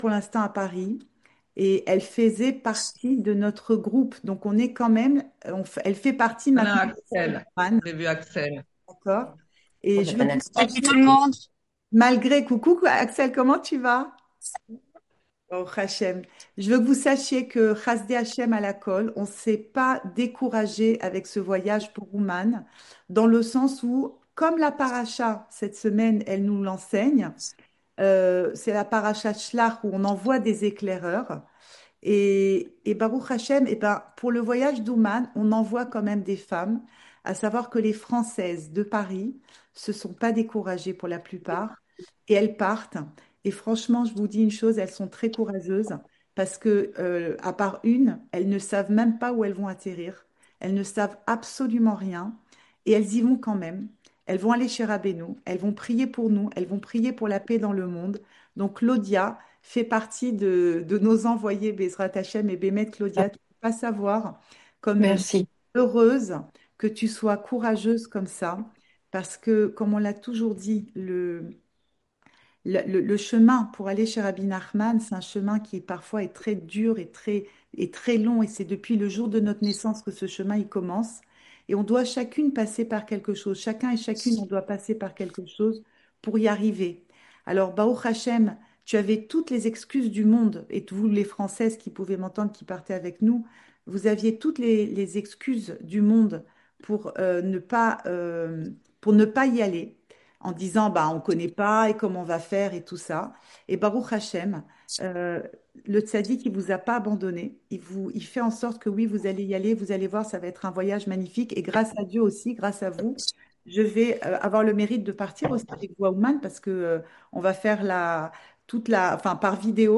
Cours audio Le coin des femmes Le fil de l'info Pensée Breslev - 18 juin 2025 18 juin 2025 La vie continue ! Enregistré en ZOOM